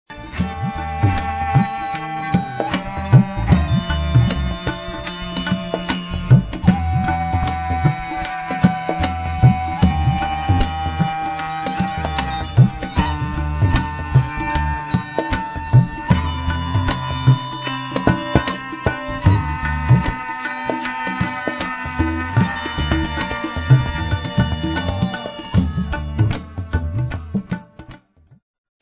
Kaval (Bulgarische Doppelflöte)
Gaida (Bulgarischer Dudelsack)
Tabla & Percussion
Cello & sampler & Live Elektronik